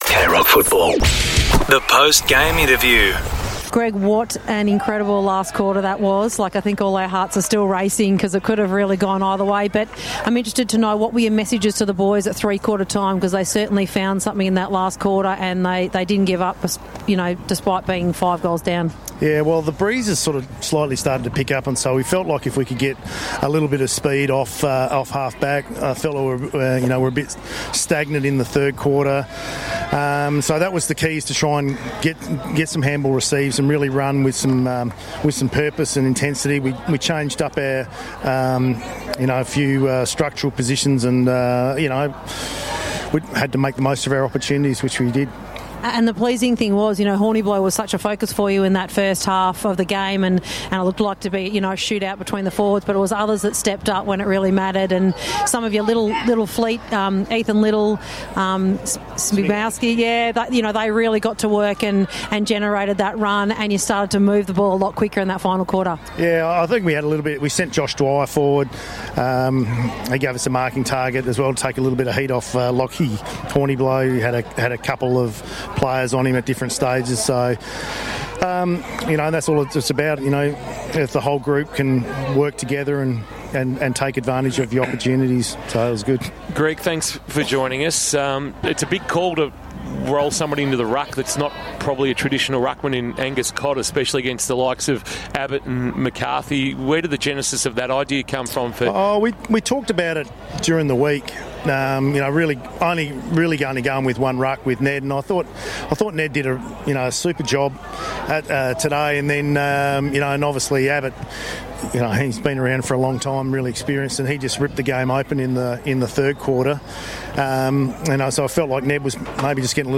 2025 - GFNL - Round 9 - Geelong West vs. Grovedale: Post-match interview